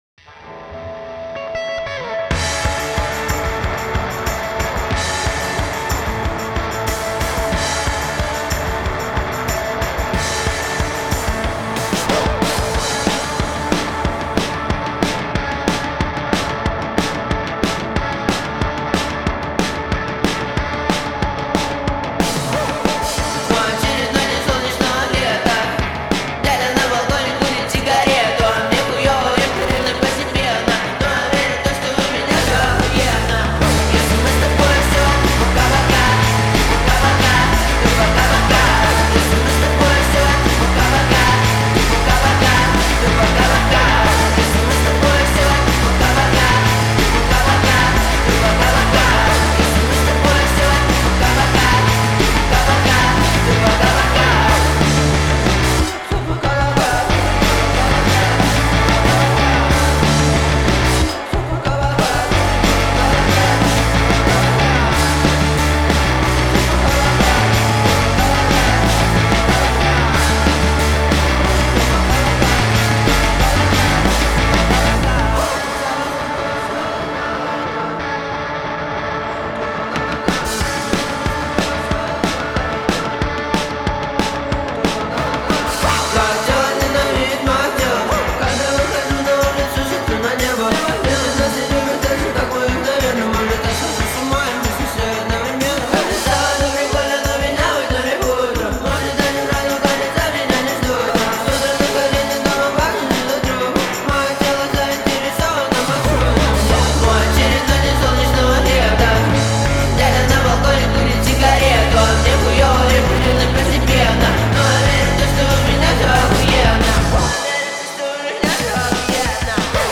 Трек размещён в разделе Русские песни / Детские песни.